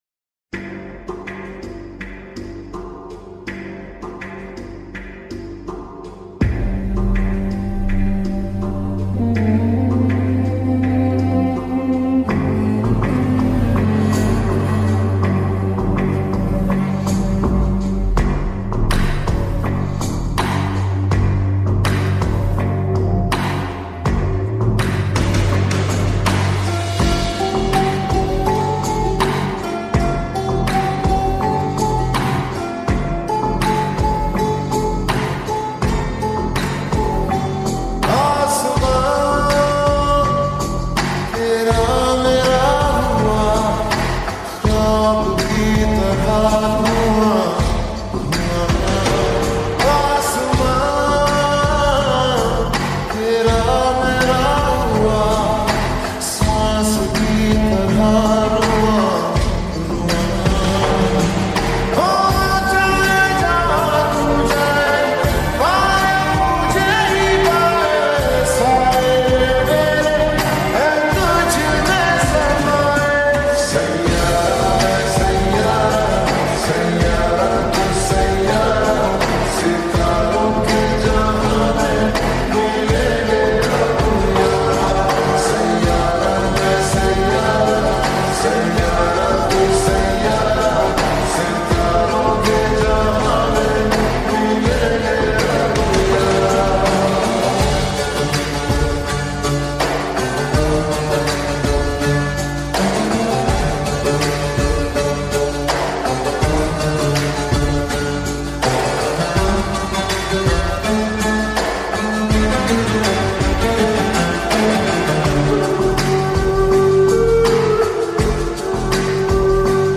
( Slowed+Reverb )